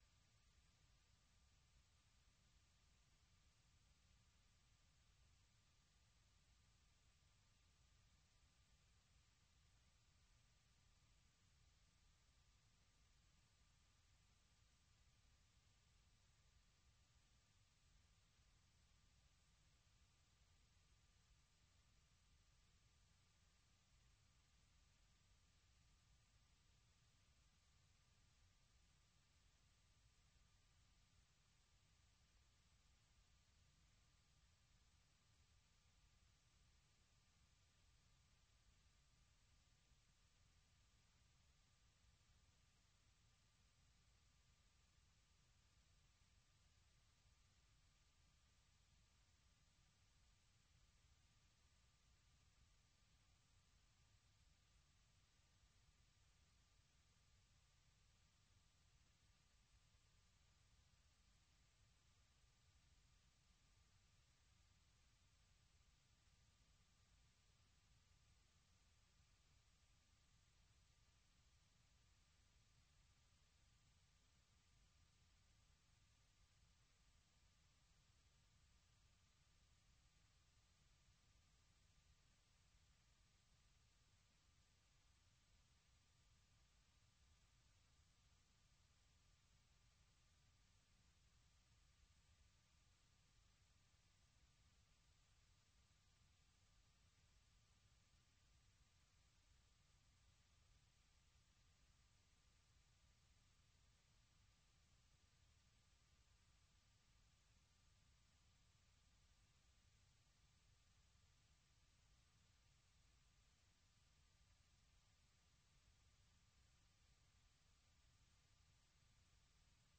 Allocution du président Donald Trump devant la session conjointe du Congrès